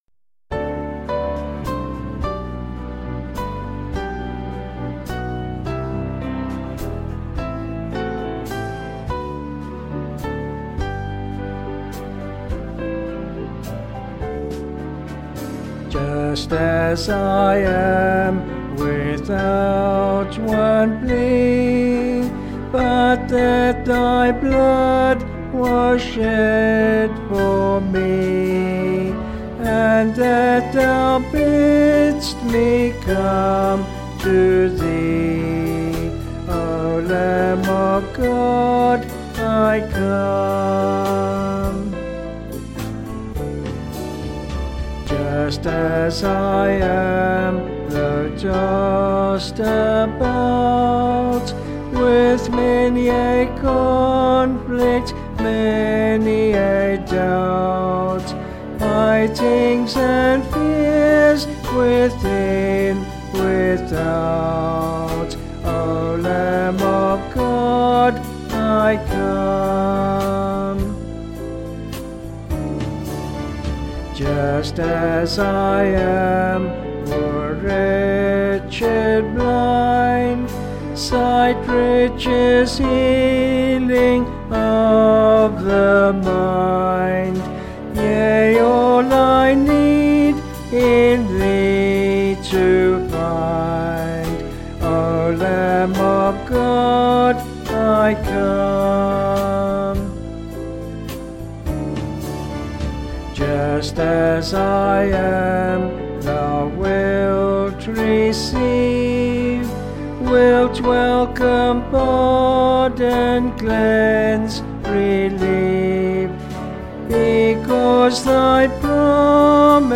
Vocals and Band   263.1kb Sung Lyrics